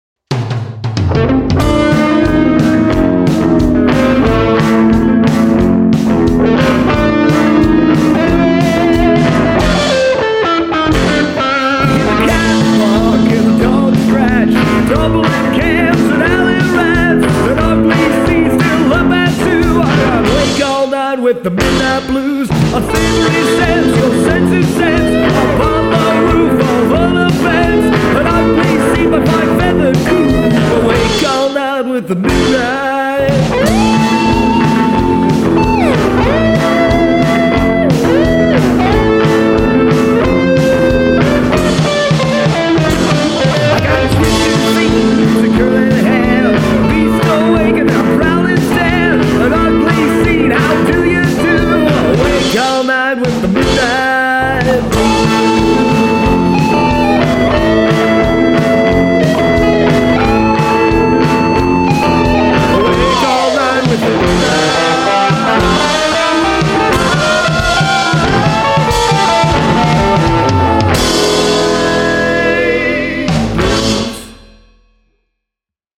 I've always been a fan of swing and have never experimented with the genre so this is a first.
Music / Pre-60s
swing blues jazz